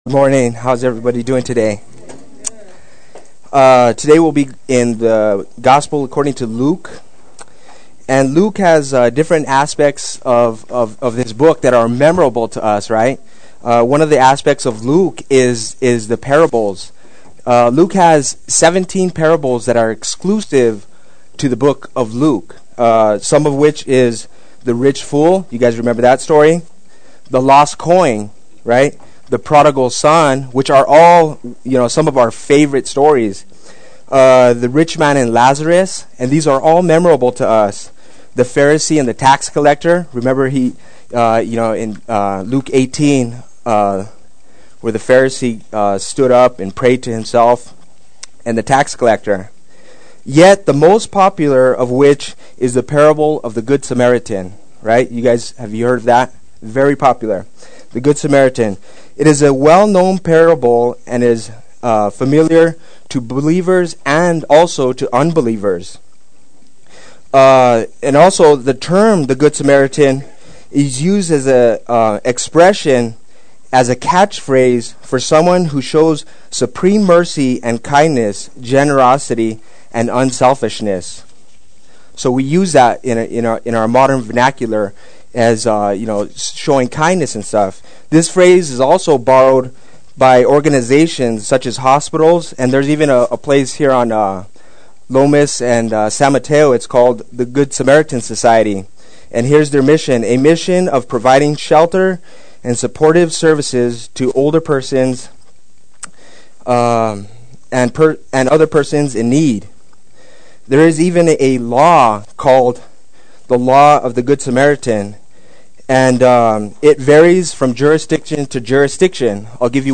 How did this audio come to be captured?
Do This and Live Adult Sunday School